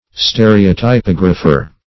Stereotypographer \Ste`re*o*ty*pog"ra*pher\, n. A stereotype printer.